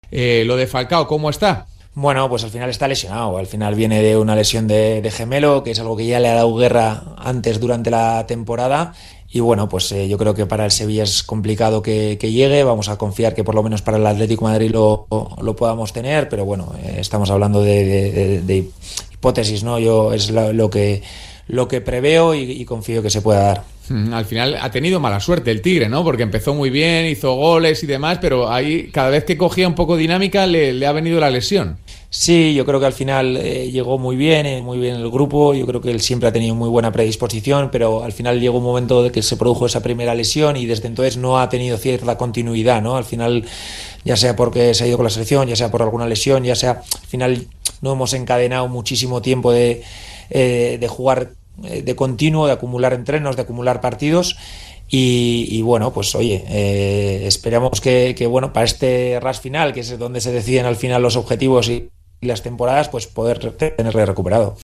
(Andoni Iraola, DT del Rayo Vallecano, en diálogo con Radio Marca)